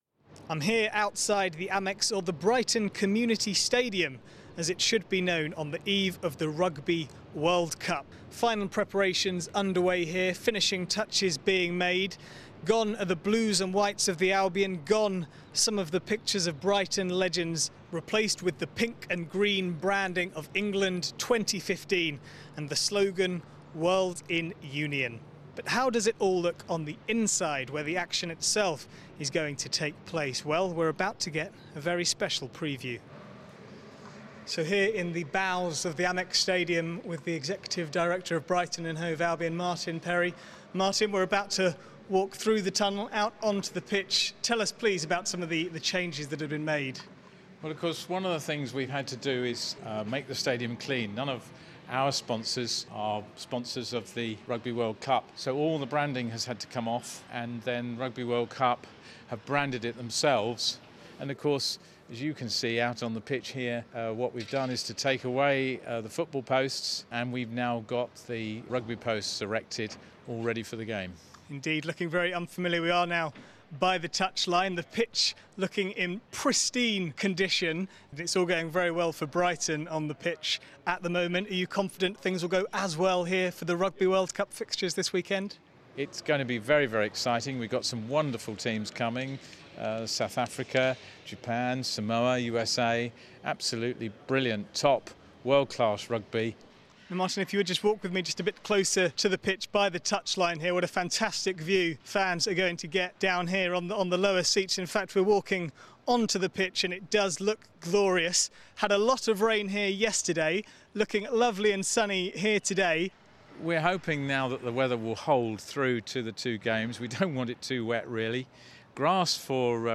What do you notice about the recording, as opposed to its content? On the pitch